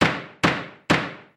На этой странице собраны разнообразные звуки, связанные с судебными процессами: от характерных ударов молотка судьи до шума зала заседаний.
В зале должна быть тишина (три стука)